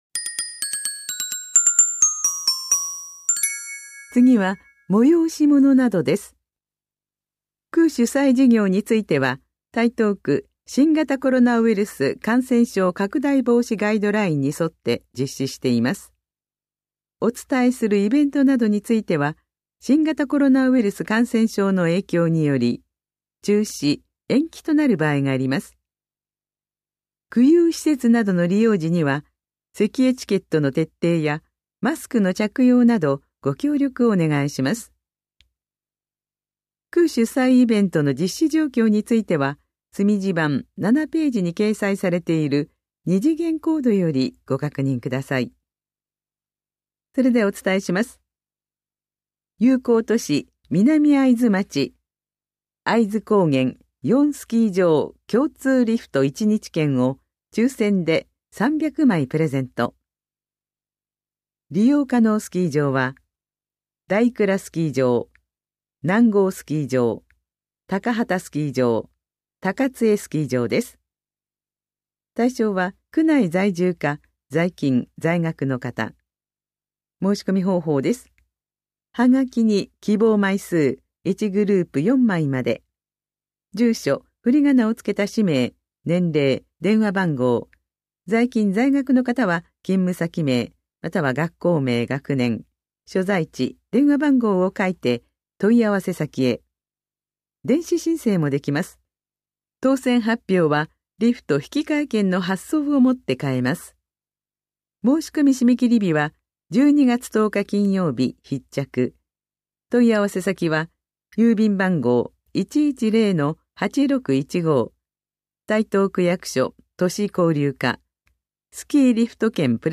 広報「たいとう」令和3年11月20日号の音声読み上げデータです。